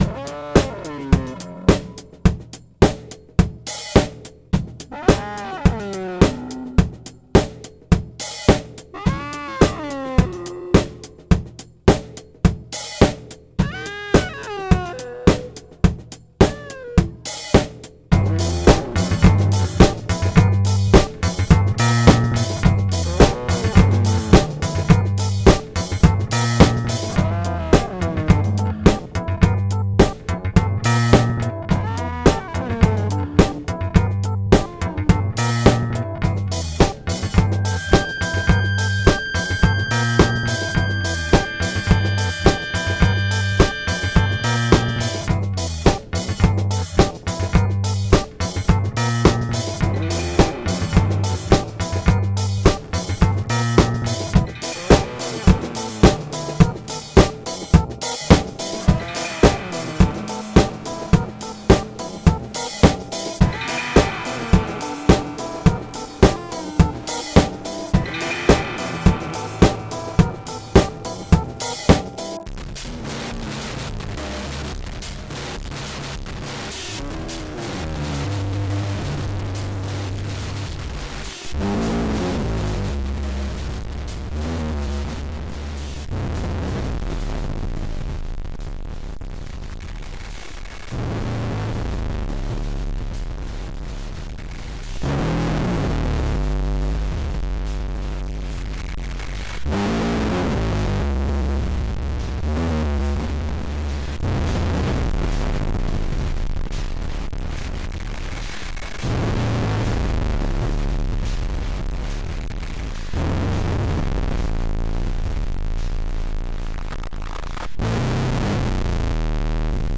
guitarra_1 bajo bateraa_1 noiseexperimentalsurf bien